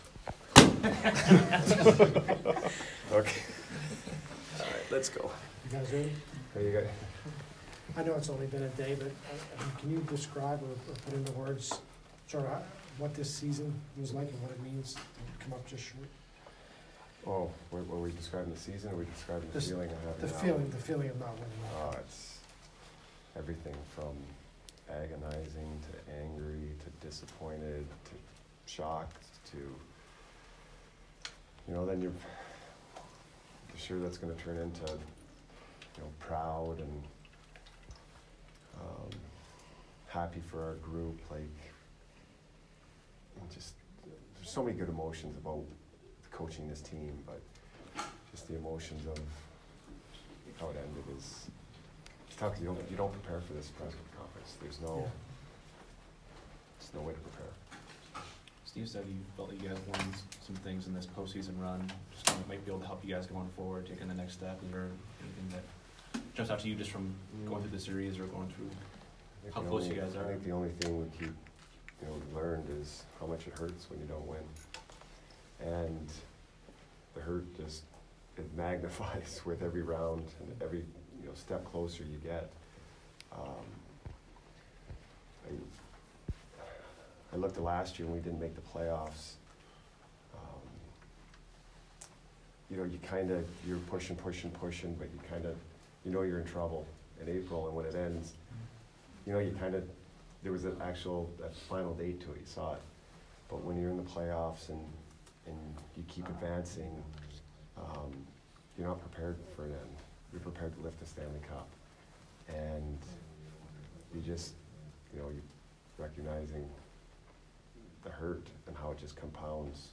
Jon Cooper Exit Interview 5/24